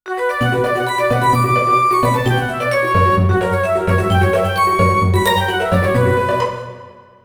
Tonalidad de Si menor. Ejemplo.
tristeza
dramatismo
melodía
severo
sintetizador